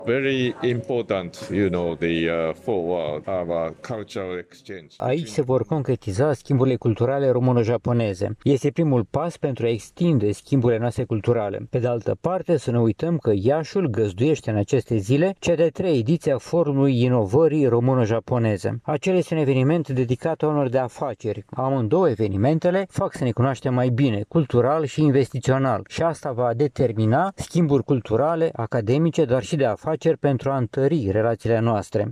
Primul Centru Cultural Japonez din regiunea de Nord-Est a țării a fost inaugurat astăzi, la Iași, în prezența ambasadorului Japoniei la București.